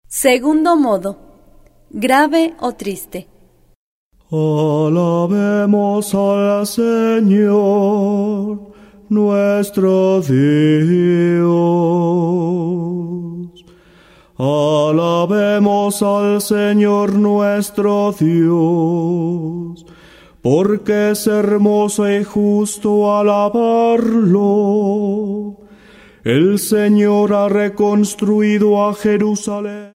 03 Segundo modo gregoriano.